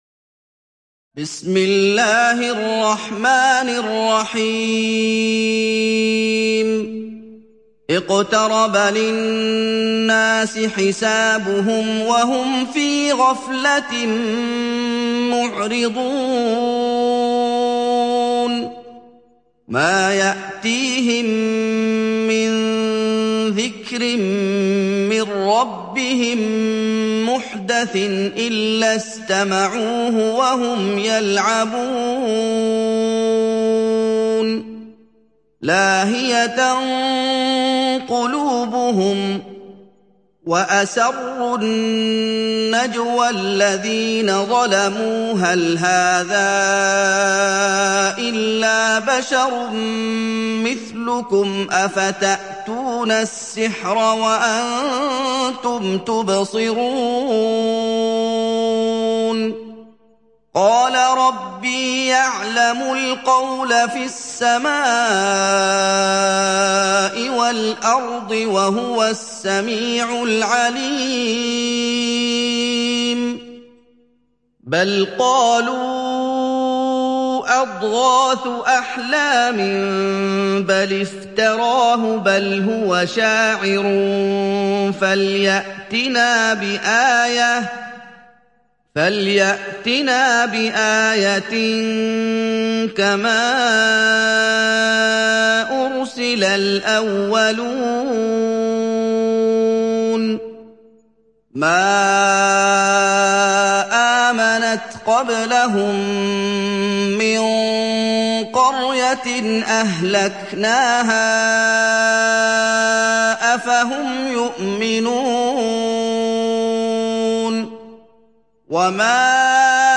دانلود سوره الأنبياء mp3 محمد أيوب (روایت حفص)